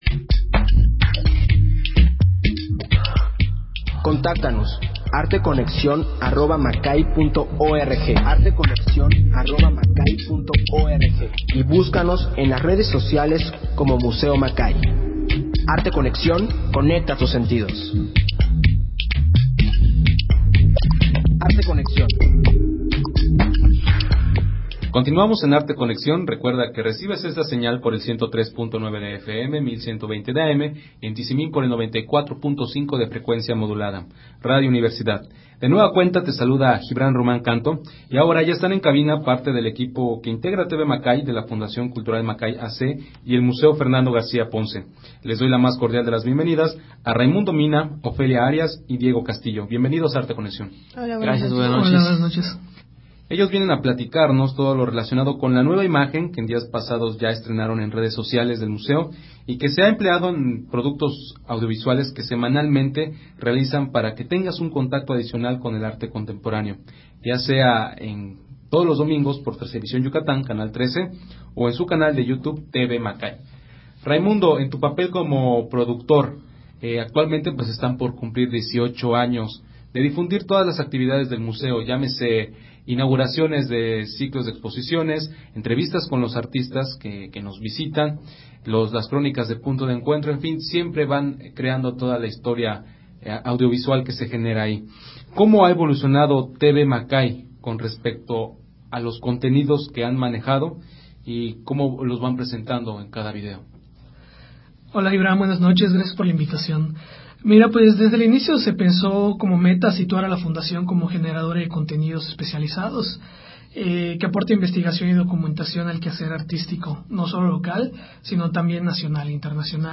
Emisión de Arte Conexión transmitida el 9 de marzo del 2017.